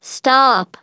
synthetic-wakewords
ovos-tts-plugin-deepponies_GLaDOS_en.wav